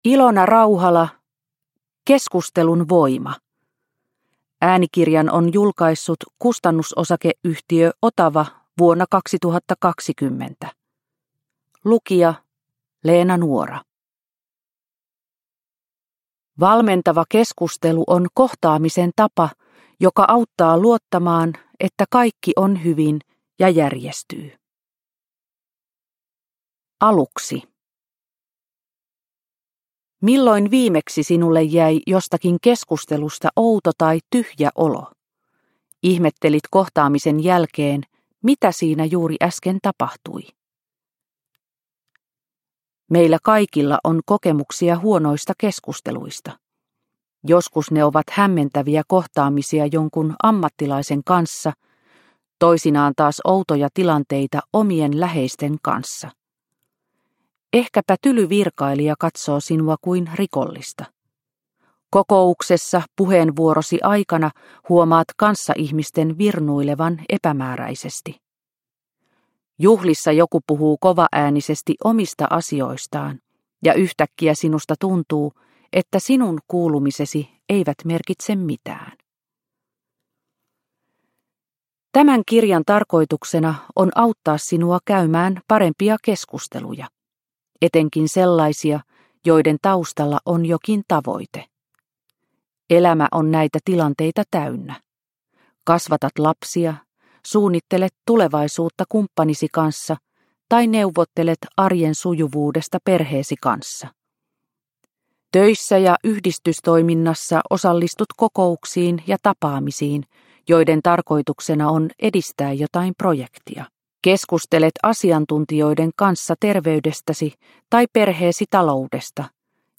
Keskustelun voima – Ljudbok – Laddas ner